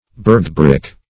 Search Result for " burghbrech" : The Collaborative International Dictionary of English v.0.48: Burghbrech \Burgh"brech`\, n. [Burgh + F. br[`e]che, equiv. to E. breach.]